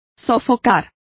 Complete with pronunciation of the translation of quash.